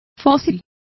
Complete with pronunciation of the translation of fossils.